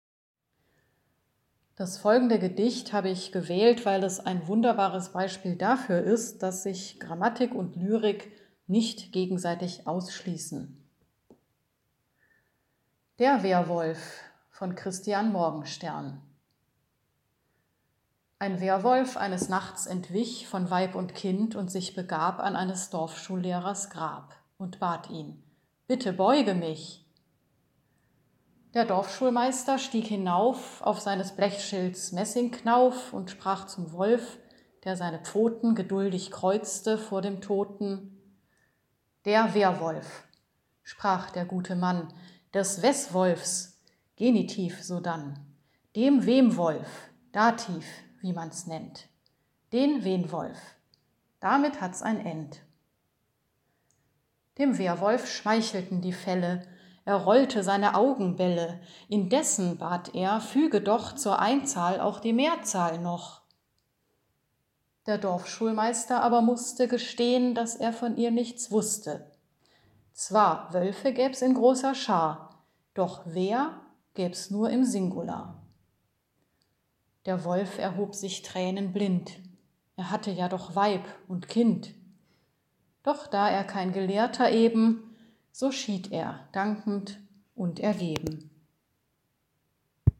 Wir haben Gedichte aufgenommen, die wir aus dem ein oder anderen Grund mögen, und Sie können sich unsere Aufnahmen anhören, an jedem Tag bis Weihnachten eine andere.